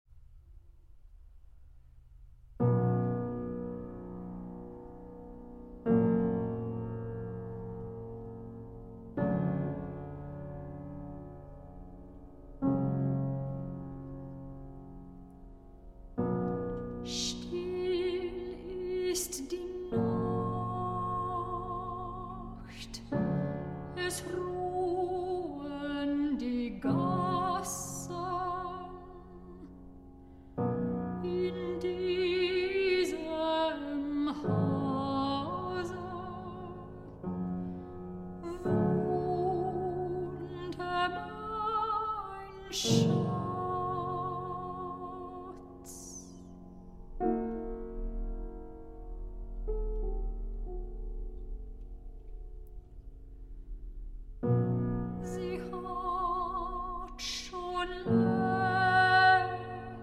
soprano
a historically informed performance
fortepiano